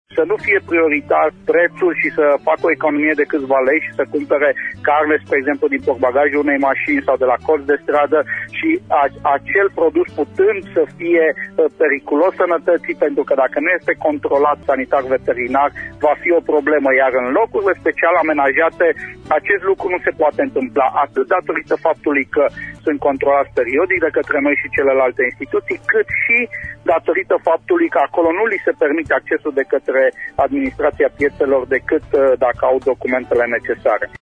Sorin Susanu, comisar şef al Comisariatului Regional pentru Protecţia Consumatorilor Braşov, a declarat azi în emisiunea „Pulsul zilei”: